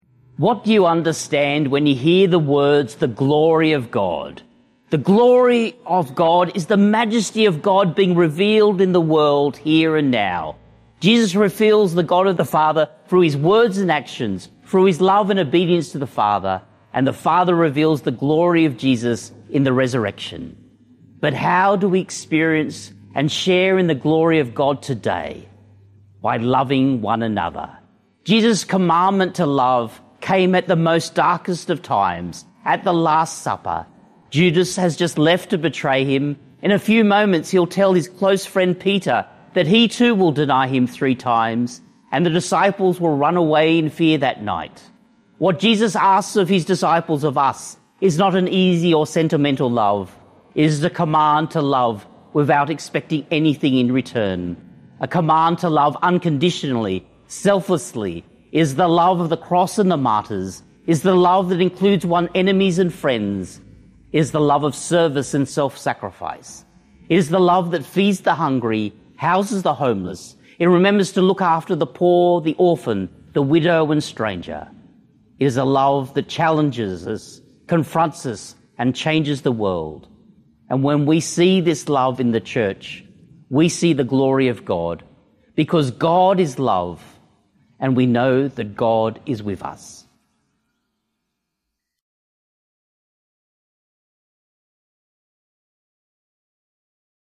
Archdiocese of Brisbane Fifth Sunday of Easter - Two-Minute Homily